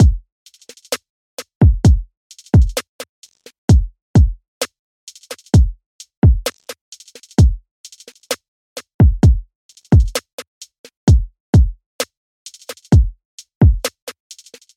Tag: 130 bpm Trap Loops Drum Loops 2.48 MB wav Key : Unknown FL Studio